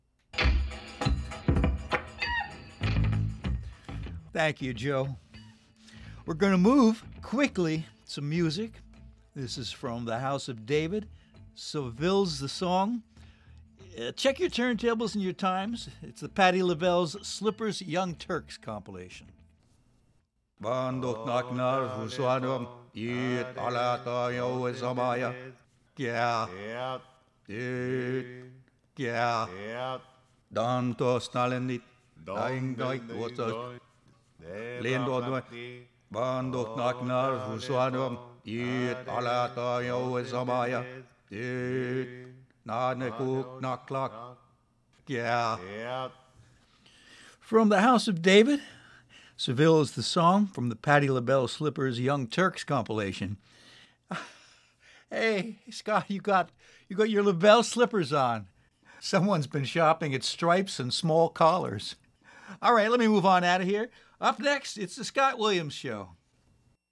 Some other bands too if they rock.